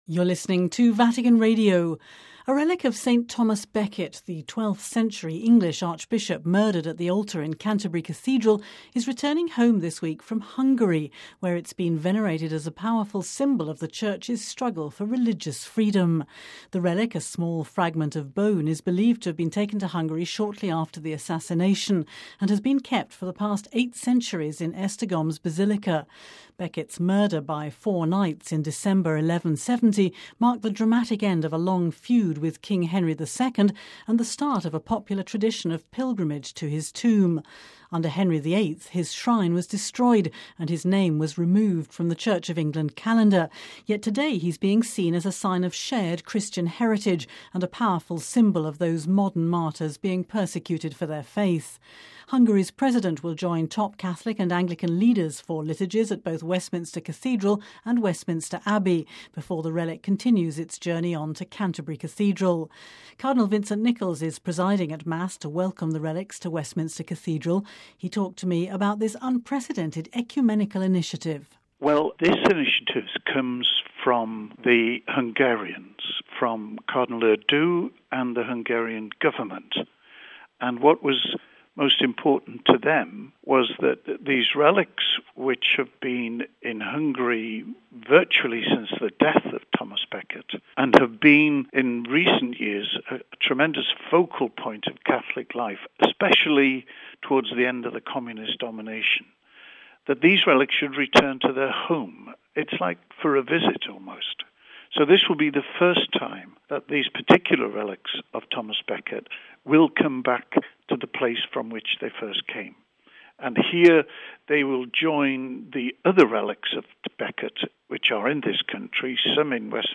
(Vatican Radio) A relic of St Thomas Becket, the 12th century English archbishop murdered at the altar in Canterbury Cathedral, is returning home this week from Hungary, where it has been venerated as a powerful symbol of the Church’s struggle for religious freedom.
Cardinal Vincent Nichols is presiding at Mass to welcome the relics to  Westminster Cathedral